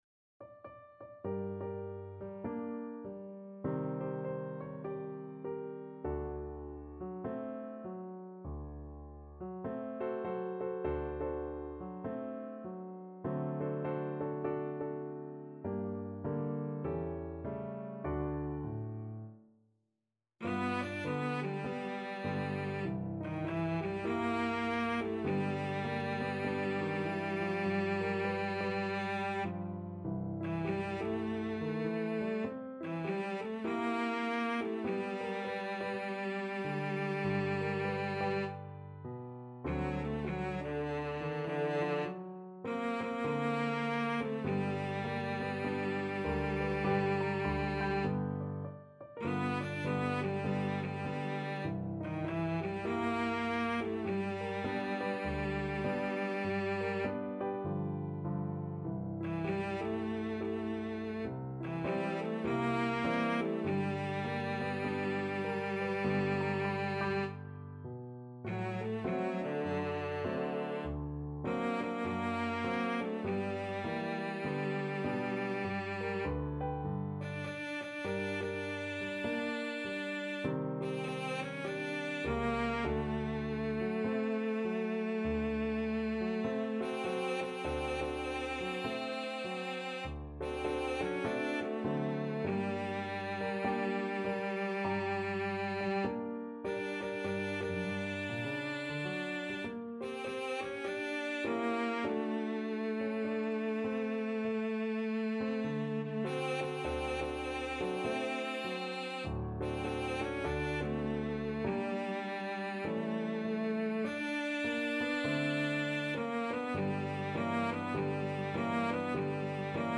4/4 (View more 4/4 Music)
Arrangement for Cello and Piano
Jazz (View more Jazz Cello Music)